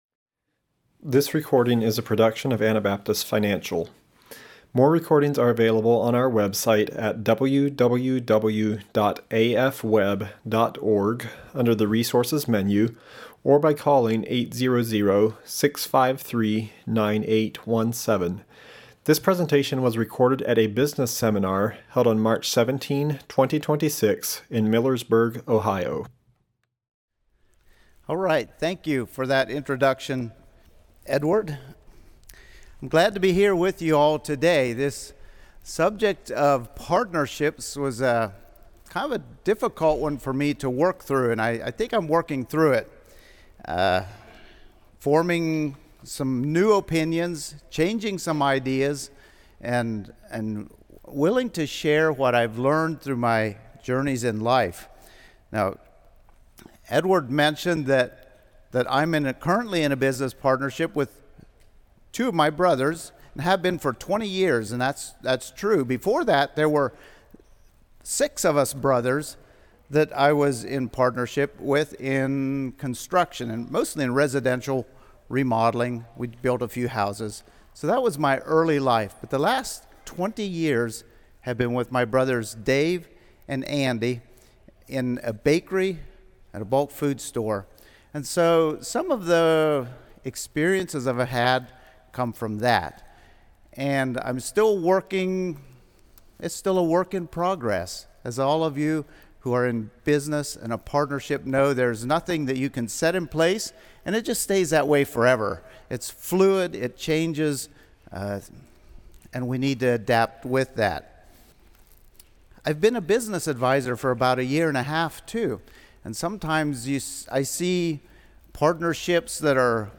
Ohio Business Seminar 2026